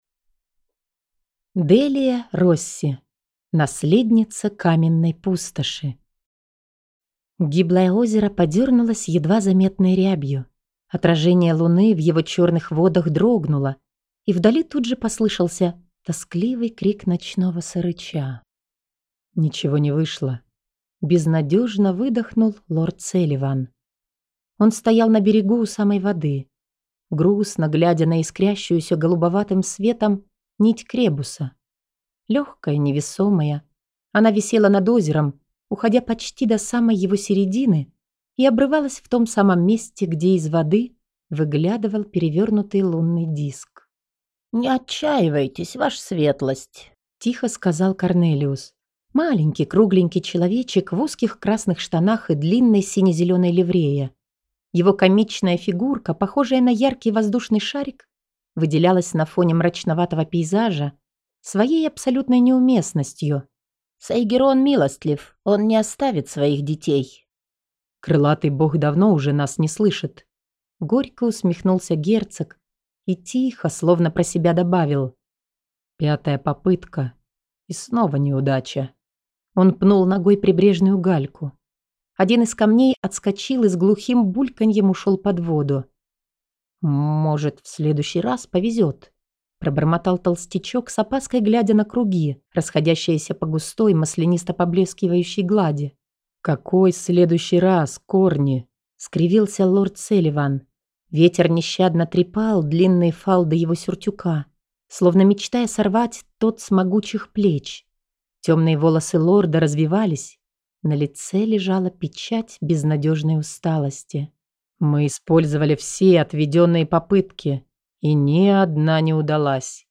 Аудиокнига Наследница Каменной пустоши | Библиотека аудиокниг
Прослушать и бесплатно скачать фрагмент аудиокниги